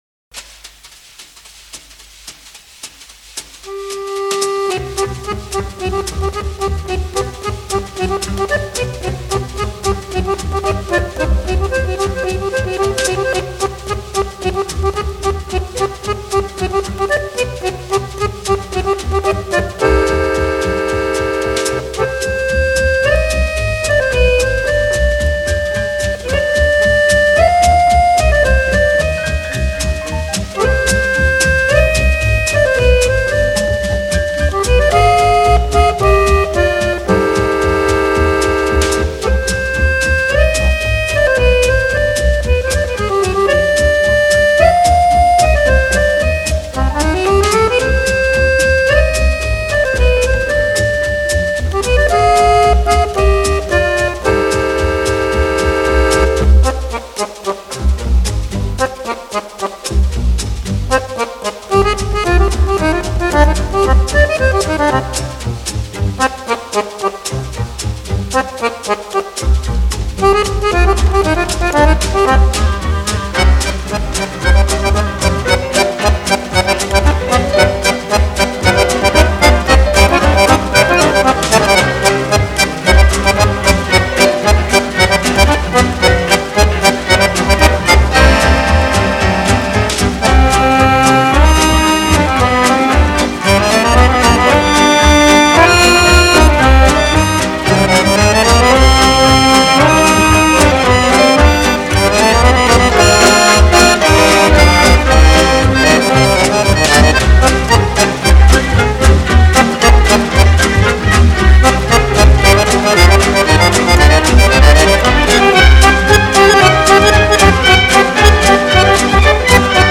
аккордеоном ...